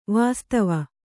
♪ vāstava